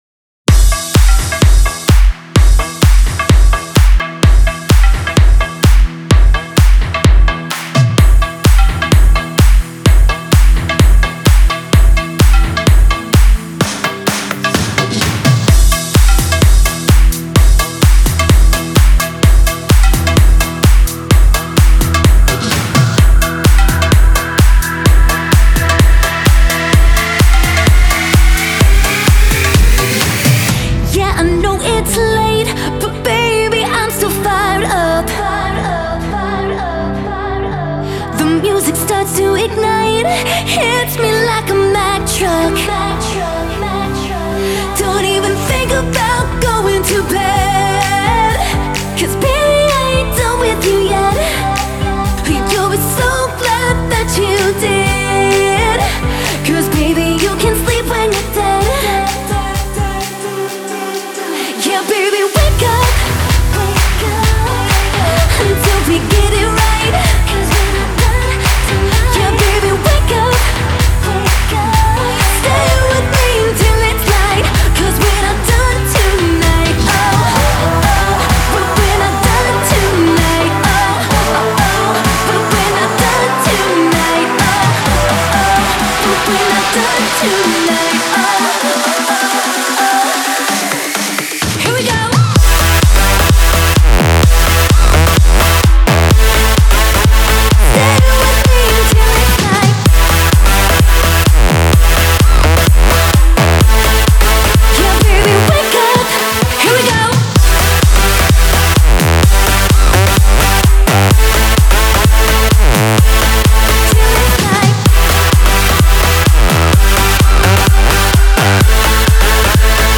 Стиль: Electro House / Electro